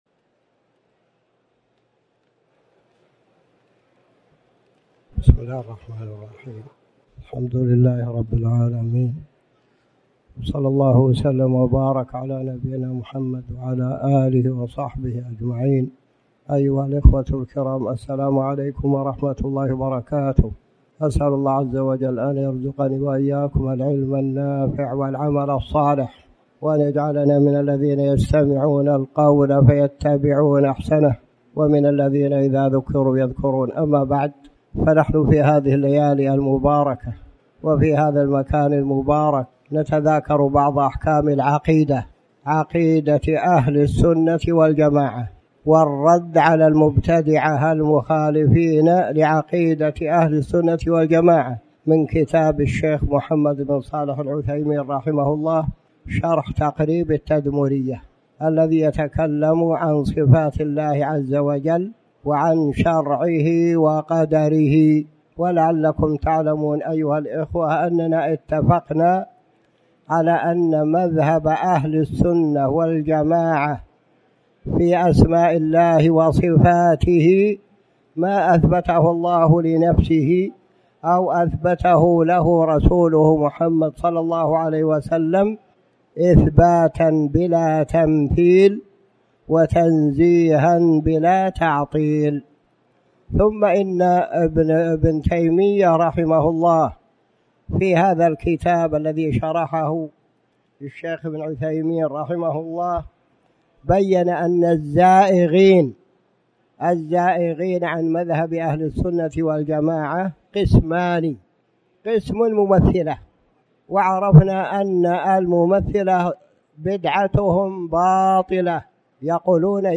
تاريخ النشر ١٠ ذو القعدة ١٤٣٩ هـ المكان: المسجد الحرام الشيخ